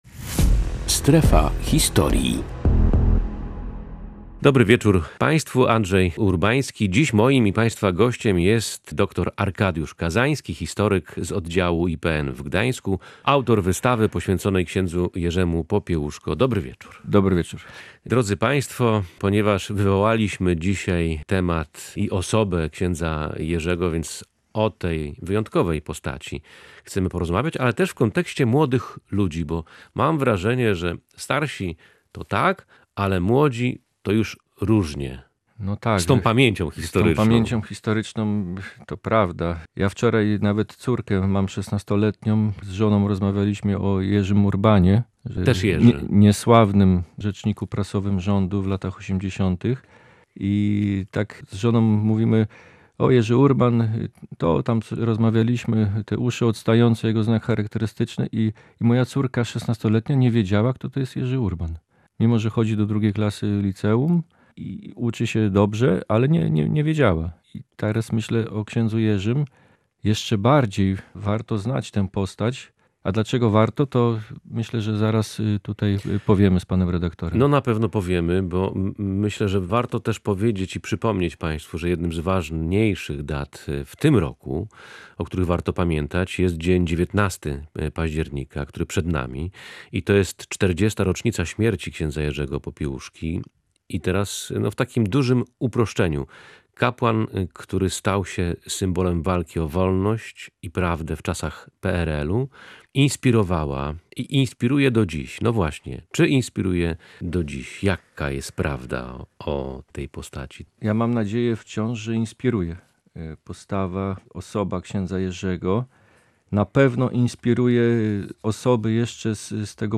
O kapłanie mówi historyk